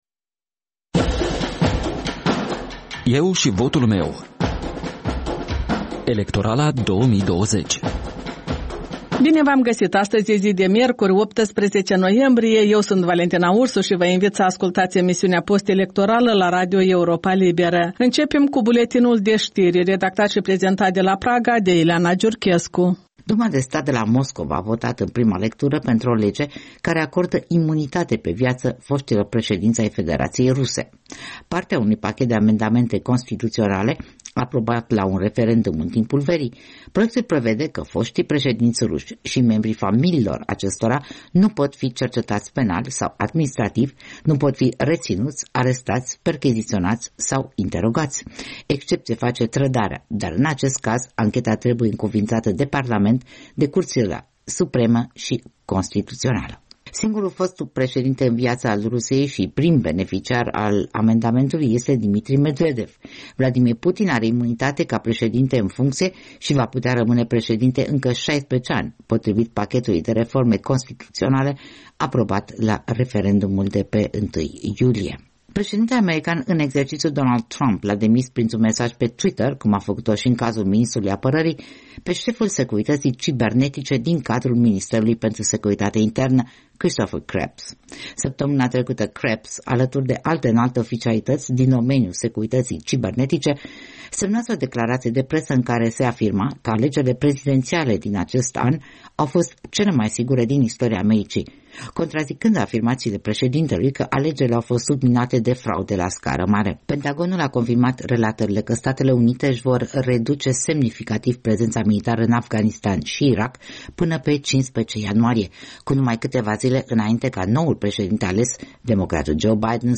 De luni până vineri, de la ora 13.00, radio Europa Liberă prezintă interviuri cu candidații în alegerile prezidențiale din 15 noiembrie, discuții cu analiști și formatori de opinie, vocea străzii și cea a diasporei.